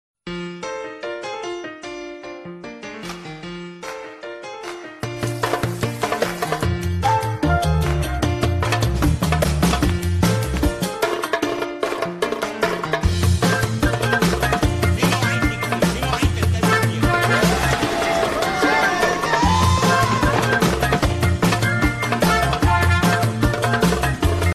Dance Ringtones